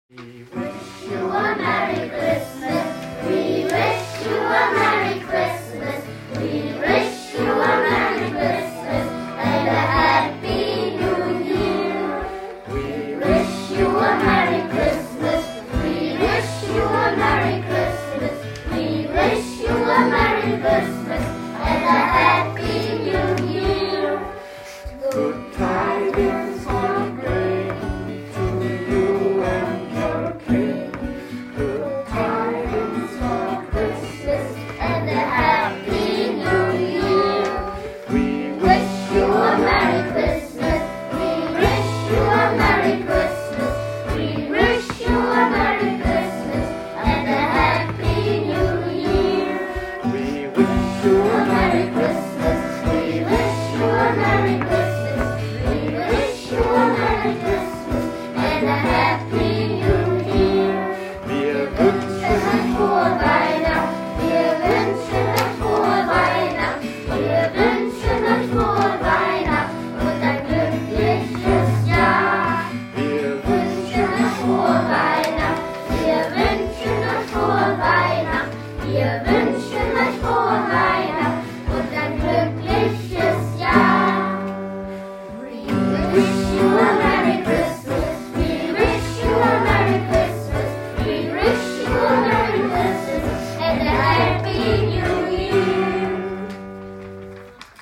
Alles übrigens aufgenommen, nach Corona-Regeln – aber wie ihr seht und hört, singen die U1-Kinder auch mit Maske toll.